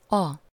Ääntäminen
Ääntäminen Tuntematon aksentti: IPA: /ˈɒ/ Haettu sana löytyi näillä lähdekielillä: unkari Käännöksiä ei löytynyt valitulle kohdekielelle.